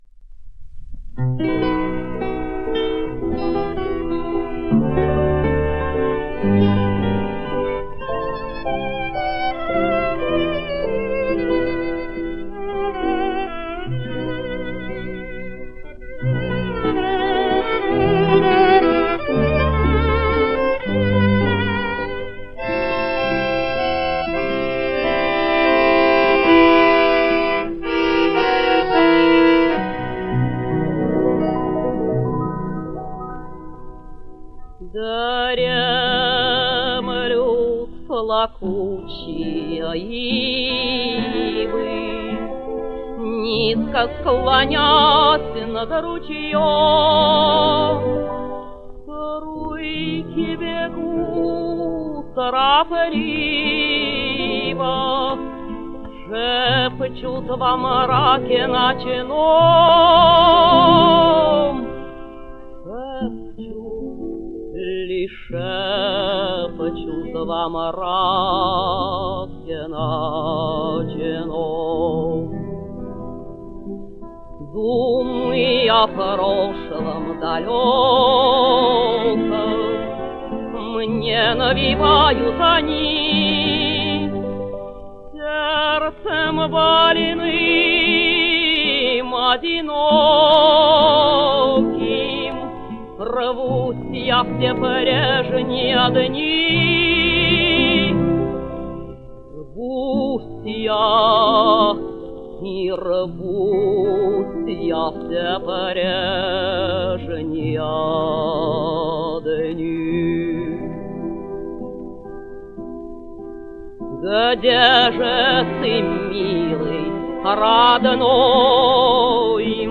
Романс?